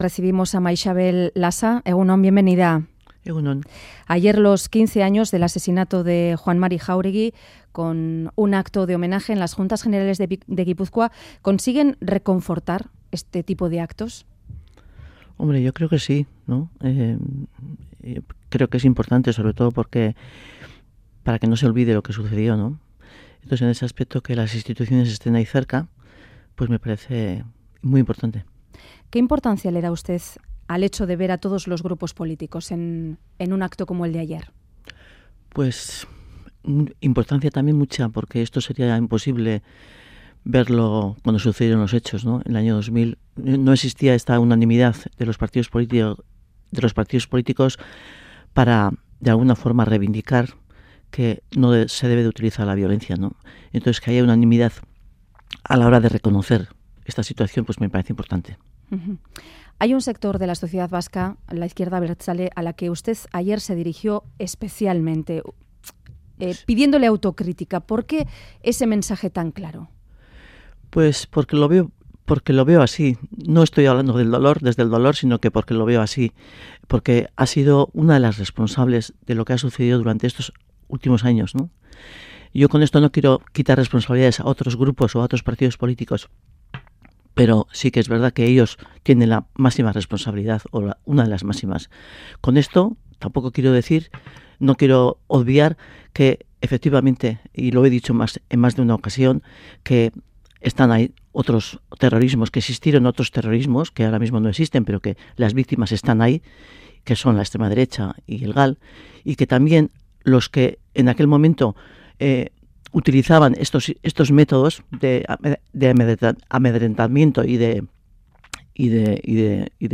Radio Euskadi BOULEVARD 'Las víctimas del GAL tienen los mismos derechos que las de ETA' Última actualización: 30/07/2015 09:44 (UTC+2) En entrevista al Boulevard de Radio Euskadi, la viuda de Juan Mari Jauregui, Maixabel Lasa, ha afirmado que las víctimas de los GAL tienen los mismos derechos que las de ETA, y que esto se tiene que solucionar en algún momento. Ha reiterado que la Izquierda Abertzale ha sido una de las máximas responsables en lo ocurrido durante años en Euskadi, y que tiene que reconocer que se equivocaron al apoyar la violencia. Ha añadido que también el Partido Socialista tiene que reconocer sus errores, y ha valorado el trabajo con los presos acogidos a la Vía Nanclares.